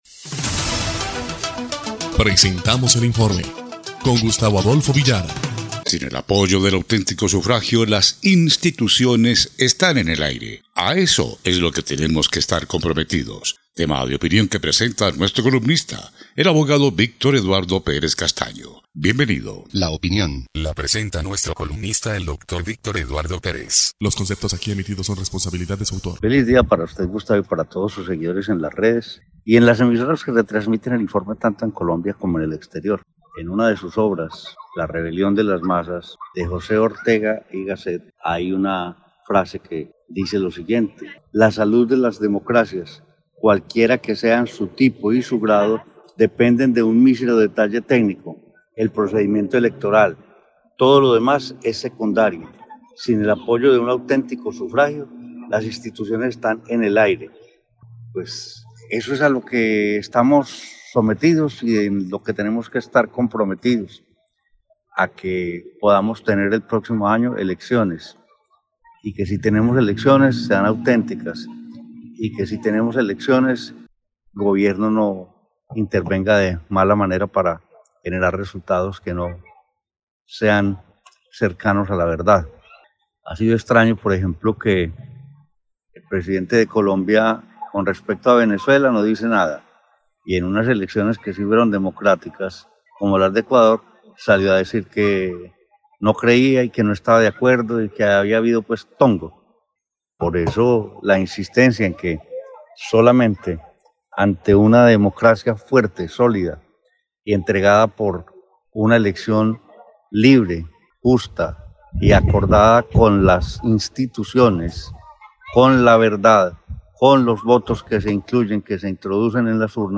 EL INFORME 2° Clip de Noticias del 21 de abril de 2025